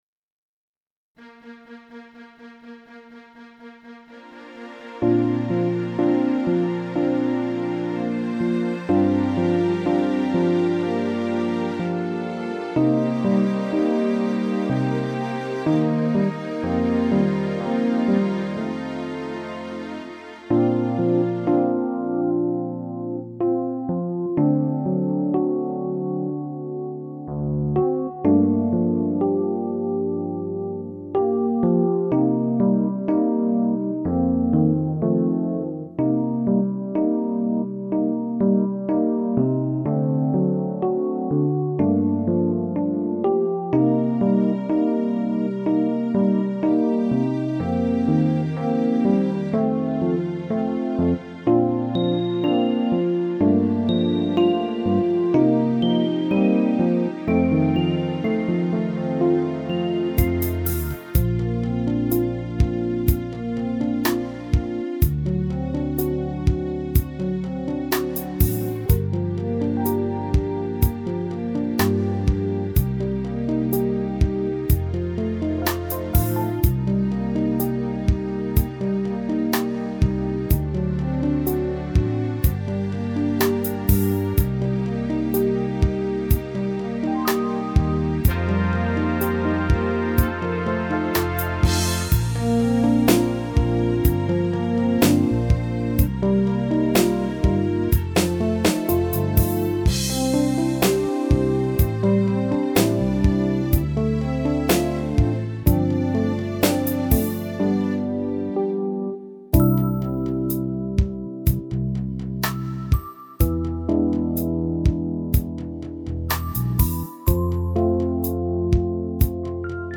• Категория: Детские песни
караоке
минусовка
грустная